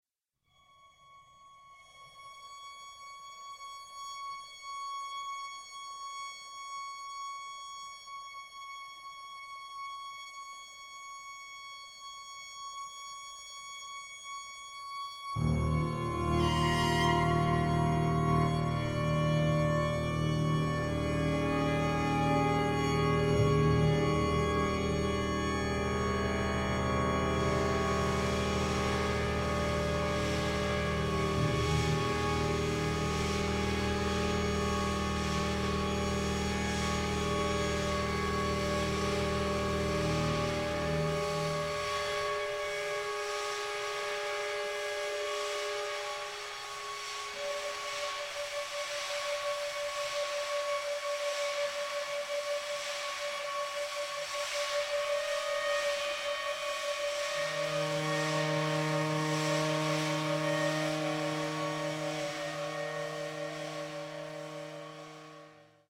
violins and violas
cellos
basses
percussion and bowed piano